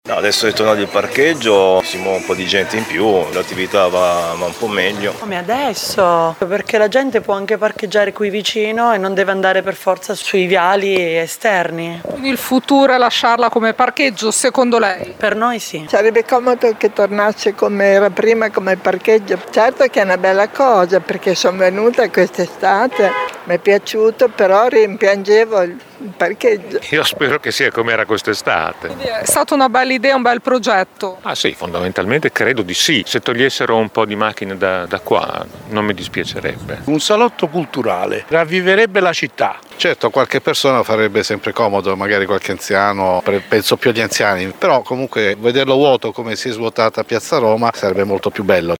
qui stotto alcune interviste